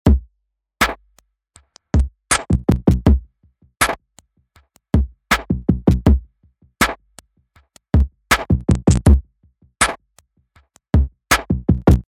Der Beat soll zwar weiterhin konkret und ein wenig schneidend bleiben, doch ganz so trocken wie er ist, fällt er aus dem Mix heraus.